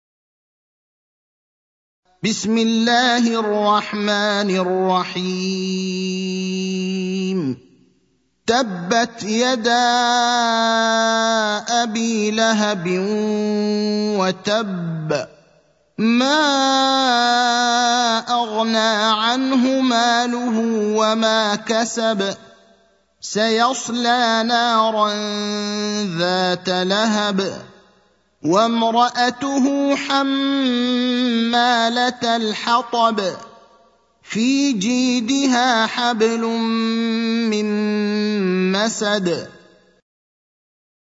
المكان: المسجد النبوي الشيخ: فضيلة الشيخ إبراهيم الأخضر فضيلة الشيخ إبراهيم الأخضر المسد (111)